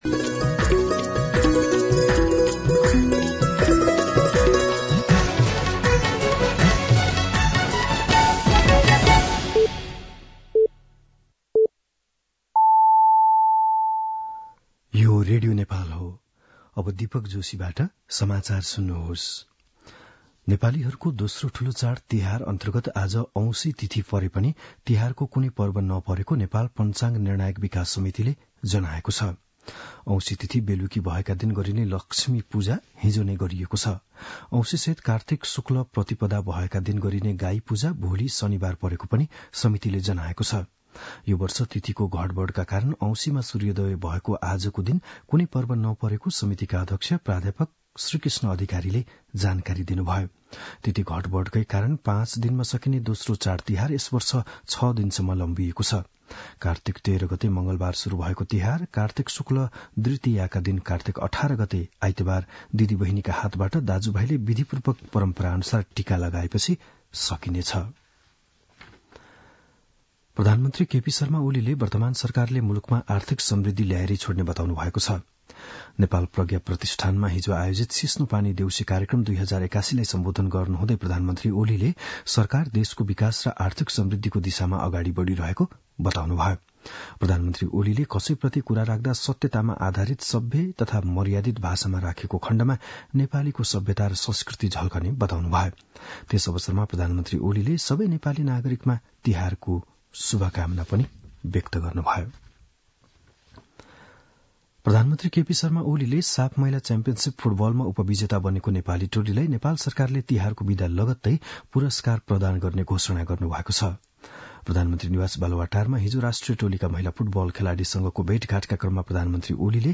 बिहान ११ बजेको नेपाली समाचार : १७ कार्तिक , २०८१
11-am-Nepali-News.mp3